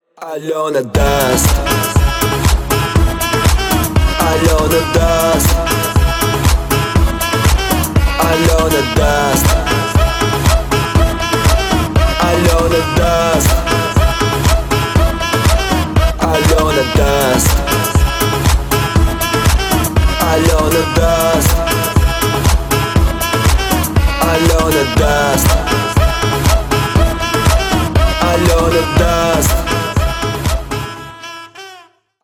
• Качество: 320 kbps, Stereo
Юмор
пародия